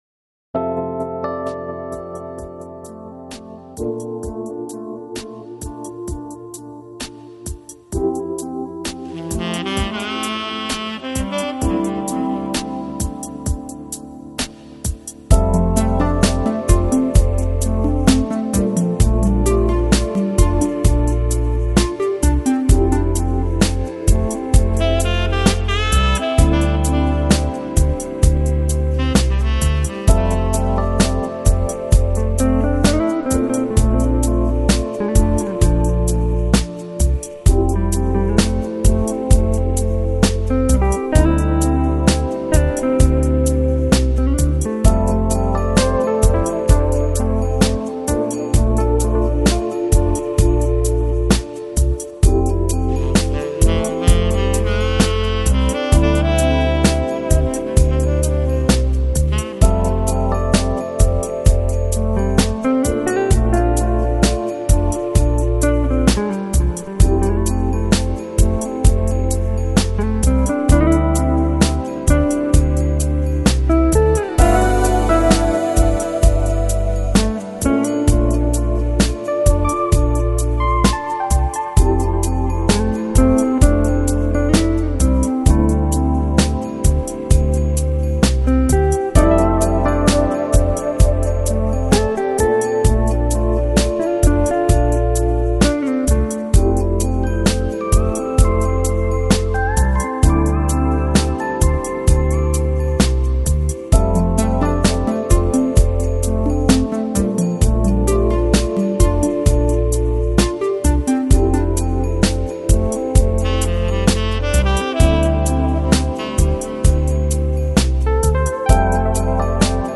Жанр: Lounge, Chill Out, Smooth Jazz, Easy Listening